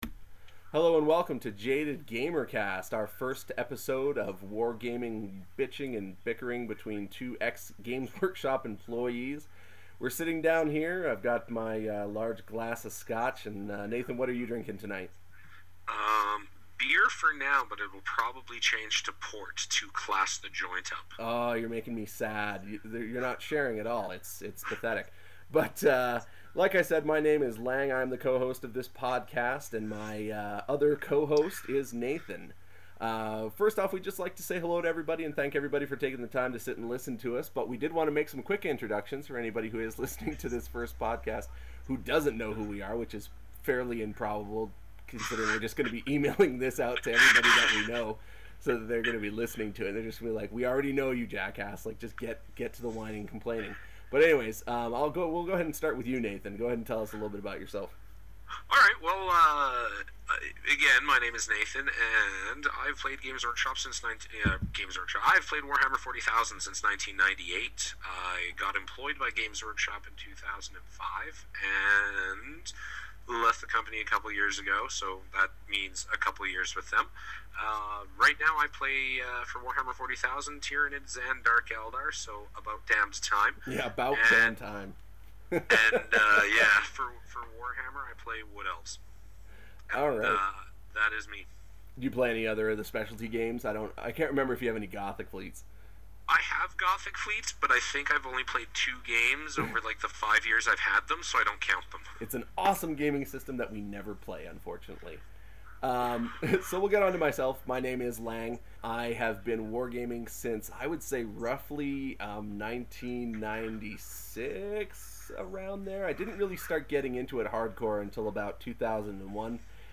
Come join in as we drink and laugh, and drink some more, while ripping into these insane ‘composition’ restrictions that are now spreading like wildfire through Europe.